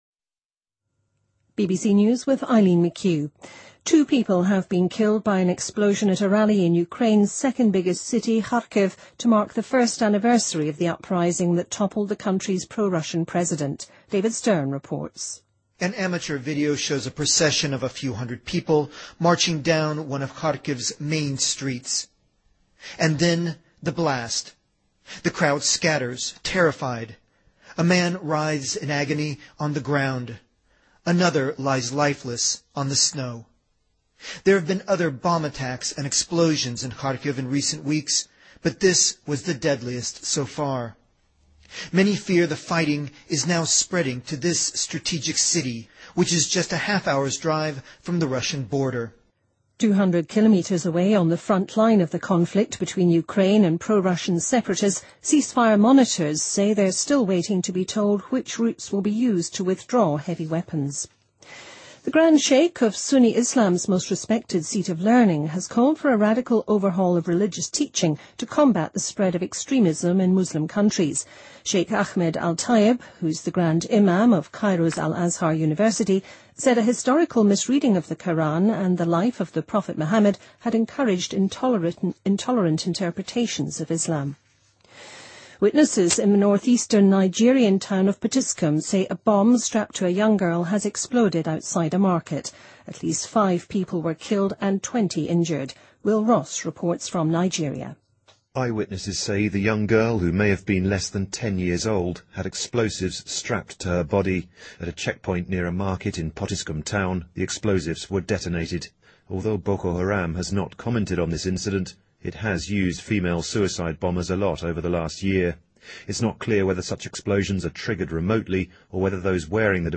BBC news